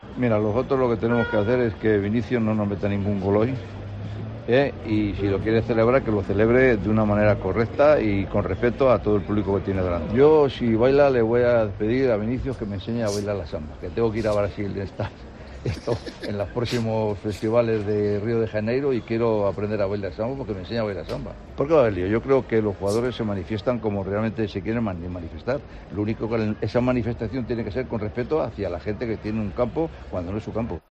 En la entrada a la comida, el presidente rojiblanco Enrique Cerezo ha atendido a los medios y ha hablado sobre toda esta polémica sobre el jugador del Real Madrid: "Lo que tenemos que hacer es que Vinicius no nos marque ningún gol hoy y si lo quiere celebrar que lo celebre de una manera correcta y con respeto hacia todo el público que tiene delante".